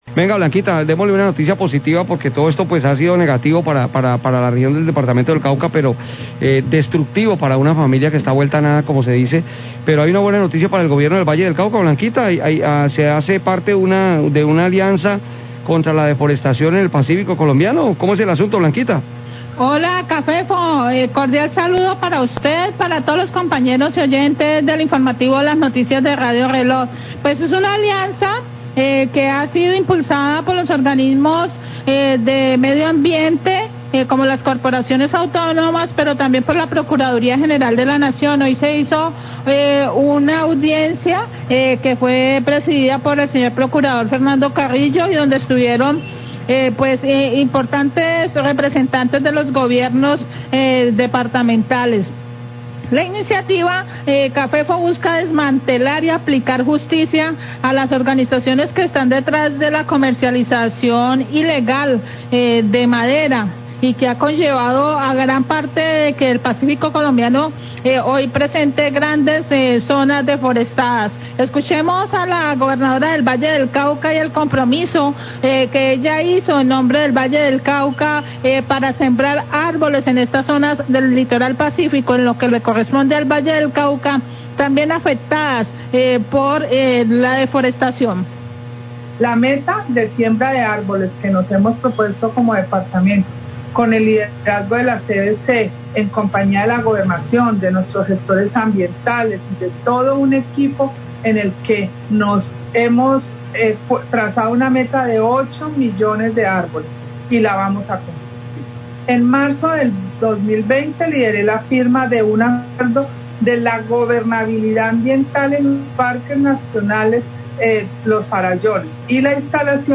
Radio
La CVC, la Gobernación del Valle y la Procuraduría General de la Nación firmaron una alianza para desmantelar las organizaciones de comercio ilegal de maderas en el pacífico colombiano. Declaraciones de la Gobernadora del Valle, Clara Luz Roldán.